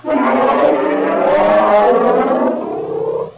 Bear In Vacuum Sound Effect
Download a high-quality bear in vacuum sound effect.
bear-in-vacuum.mp3